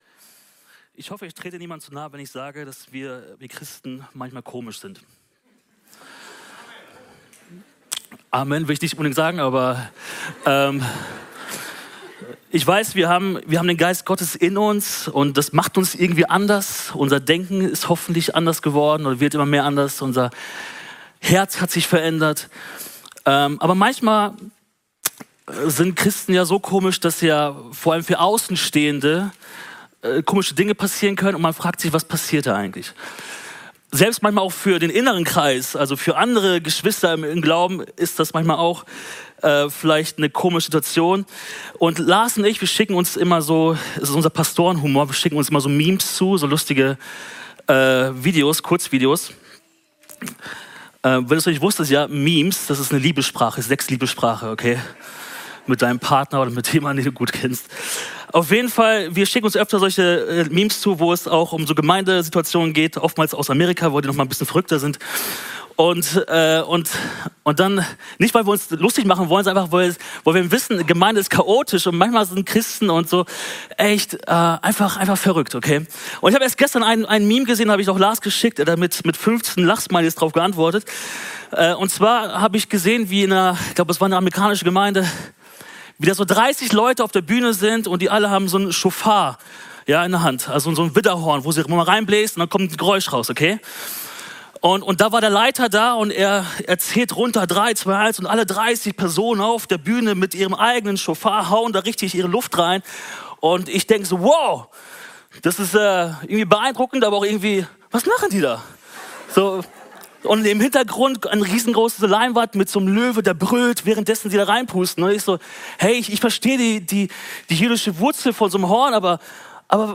24 - Einzelne Predigten